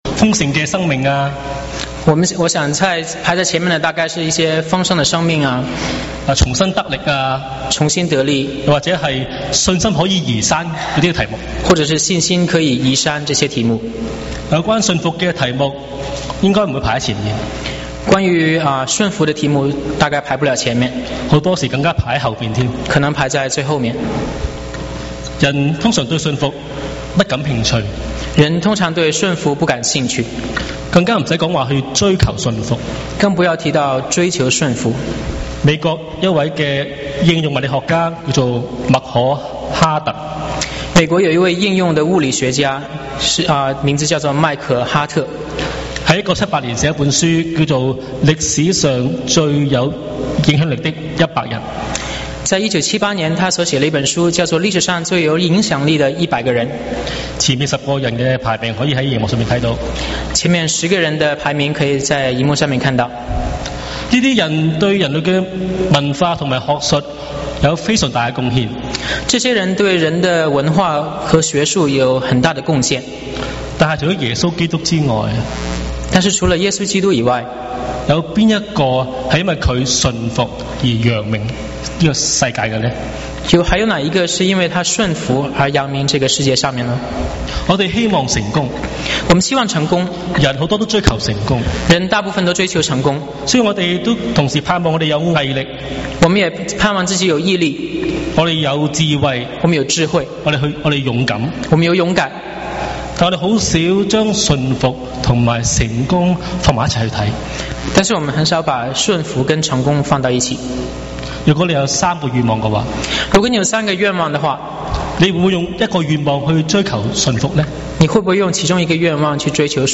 受苦節崇拜 | 波士頓華人佈道會